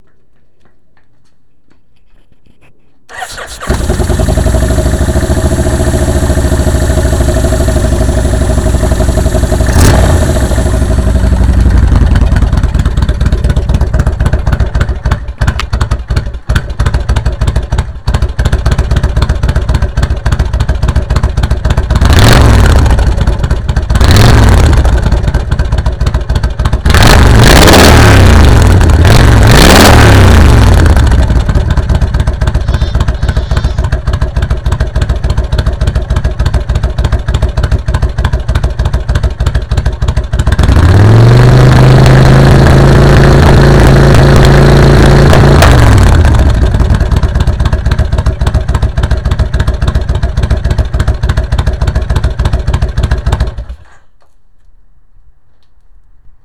Auspuff-Sounds